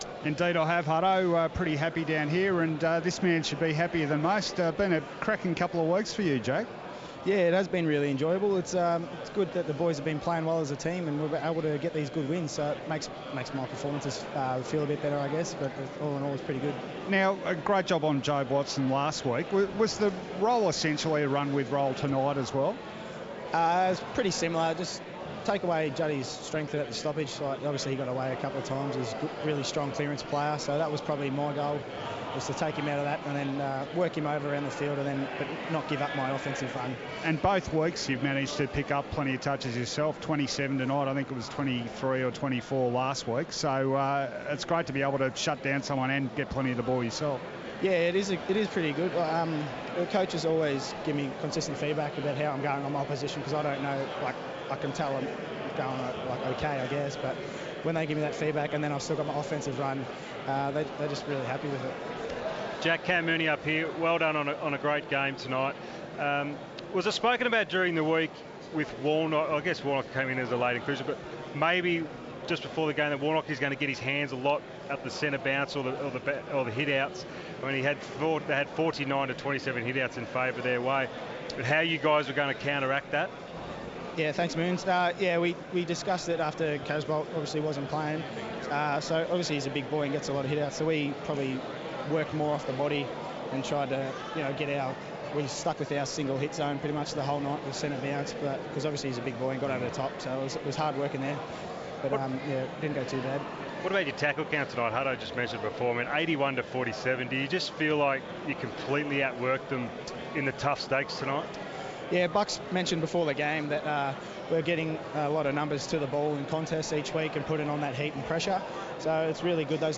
Collingwood recruit Jack Crisp chats with the team following the Pies' 75-point win over Carlton on Round 5 Friday Night Footy